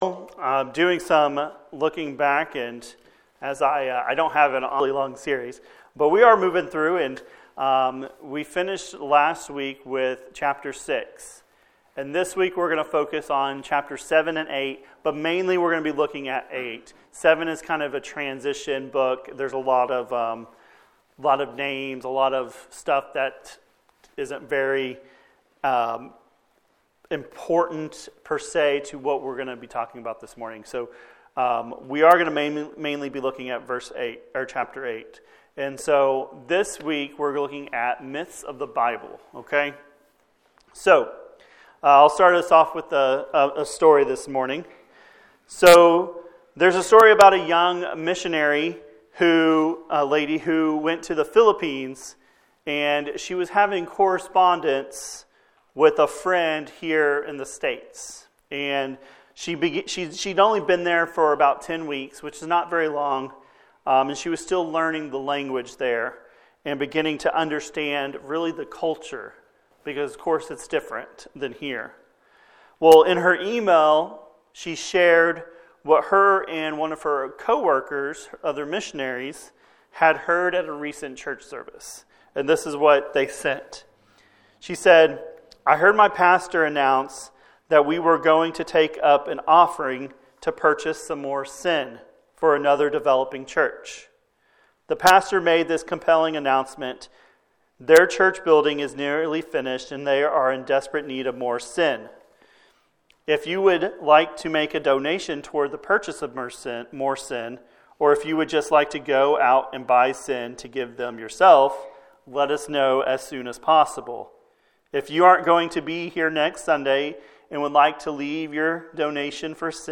wp-content/uploads/2023/02/Myths-of-the-Bible.mp3 A sermon from Nehemiah chapters 7 & 8.